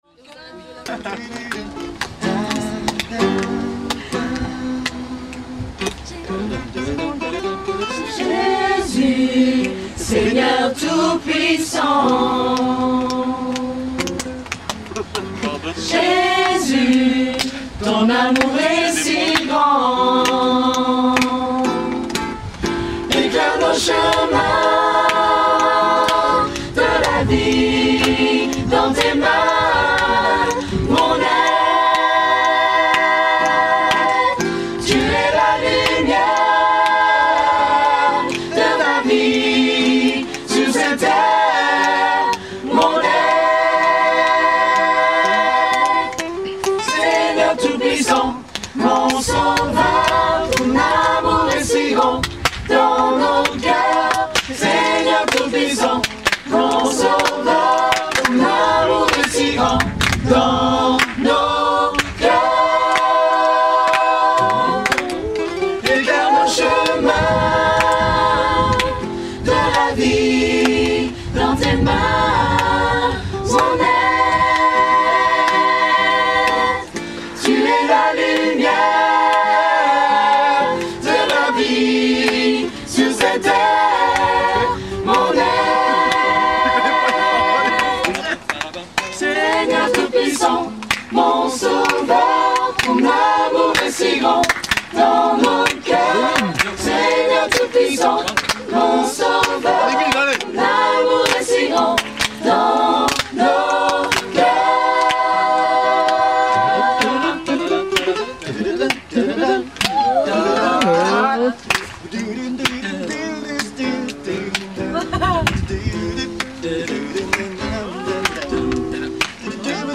(acoustique)
Voici quelques extraits audio de la soirée récréative !